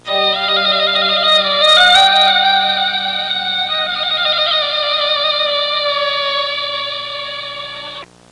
Arabian Intro Sound Effect
Download a high-quality arabian intro sound effect.
arabian-intro-1.mp3